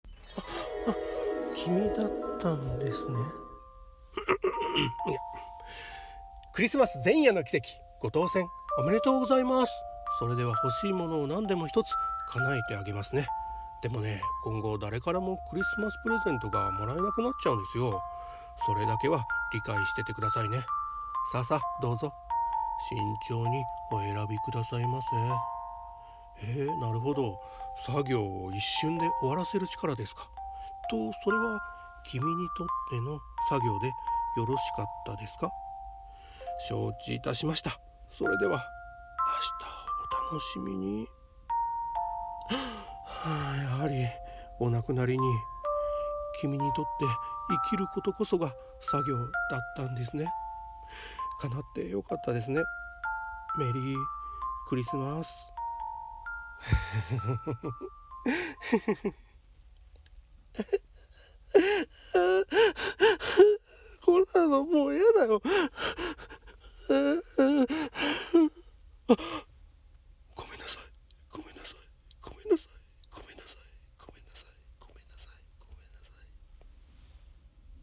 🎄 MerryX'mas 🎄 【 クリスマス台本 一人声劇 朗読